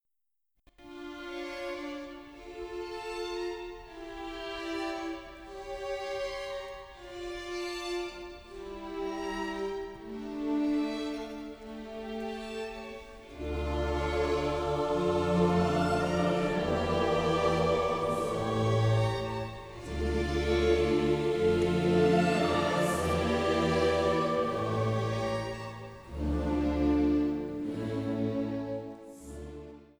Orchester, Klavier